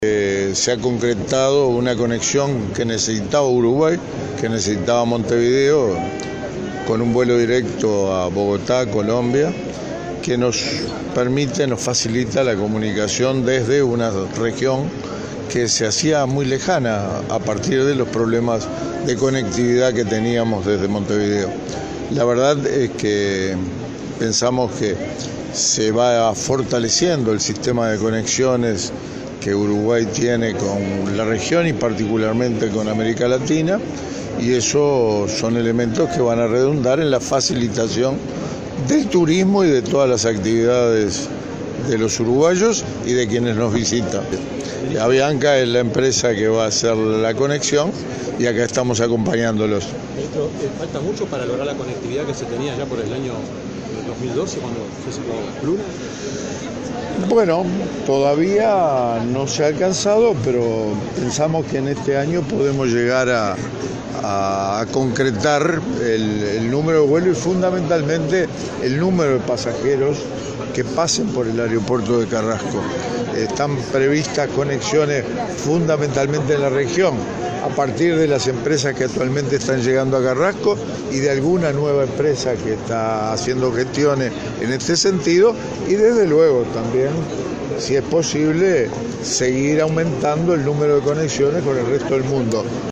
Un vuelo directo diario entre Montevideo y Bogotá fortalece el sistema de conexiones aéreas con la región y facilita negocios y turismo. Así lo manifestó el ministro de Transporte, Víctor Rossi, a la prensa durante el vuelo inaugural de Avianca que unirá por primera vez ambas capitales. Rossi dijo que este año se prevé aumentar el número de conexiones con el resto del mundo.